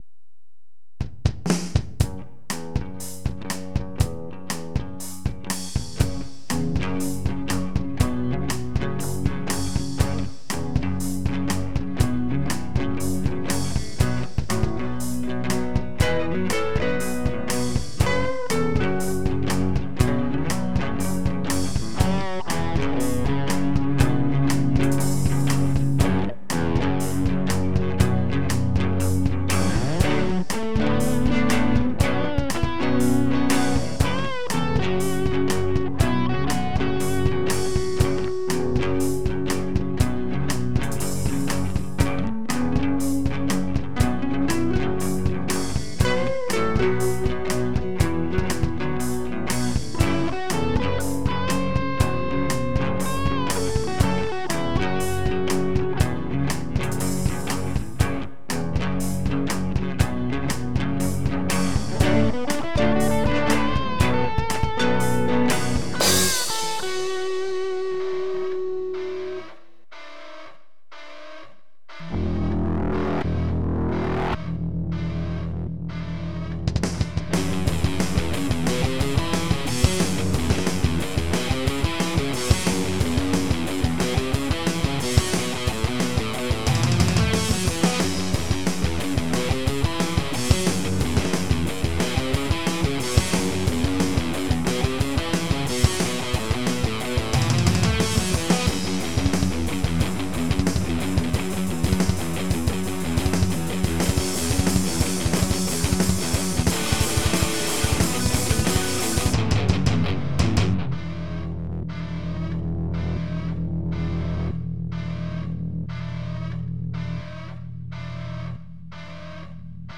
j\'ai bloqué sur la grosse caisse un peu trop spéciale.